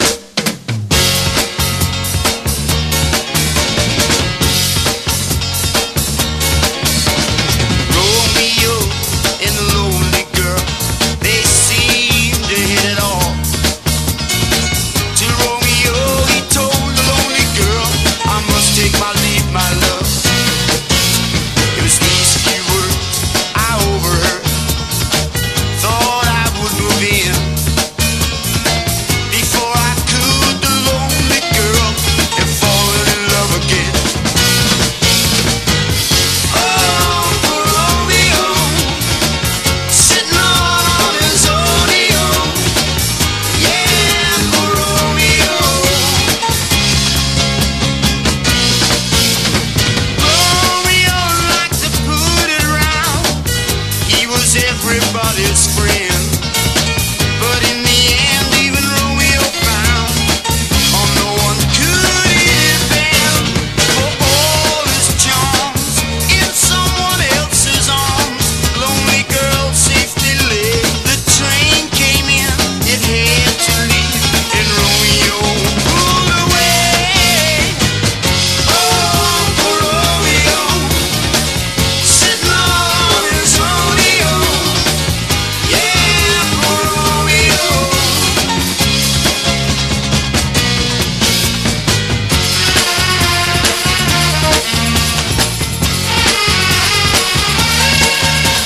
ROCK / PUNK / 70'S/POWER POP/MOD / NEW WAVE / WAVY POP
POWER POPタイプの素晴らしいアレンジ！